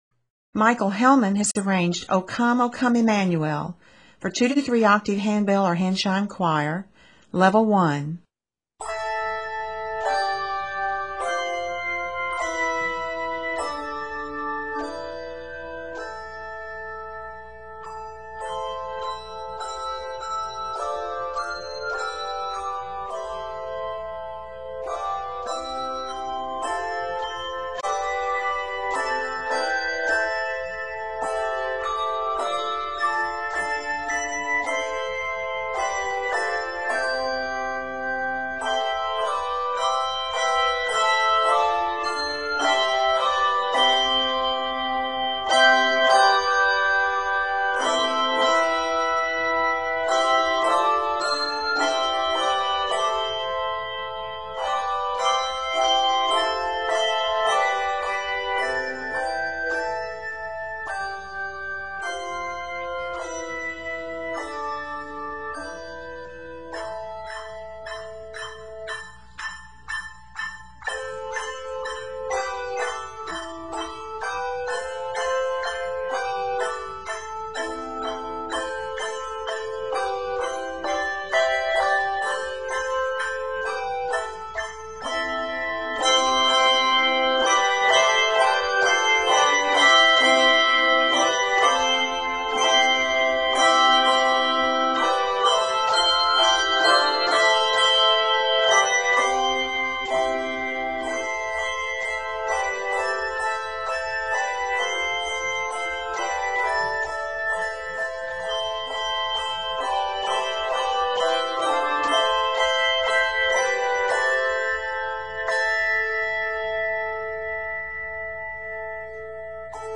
for 2-3 octave handbell or handchime choir